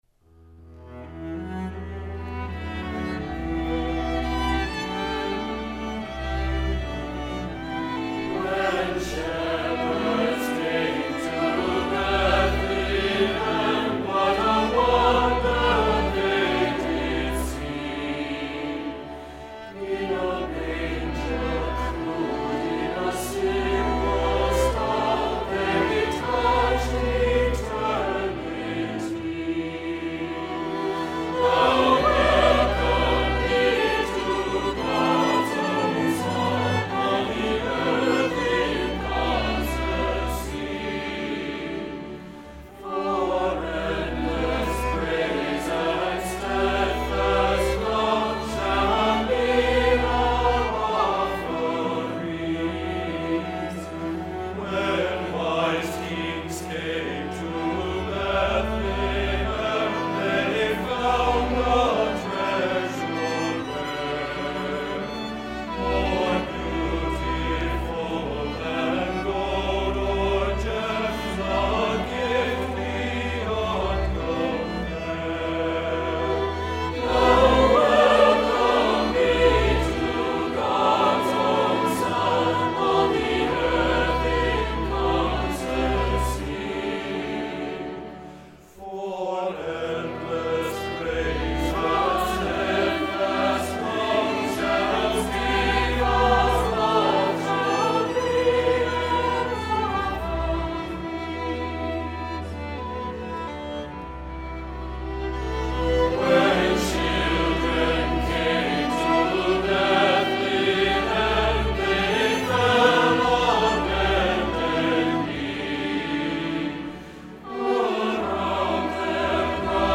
Voicing: SAB and Piano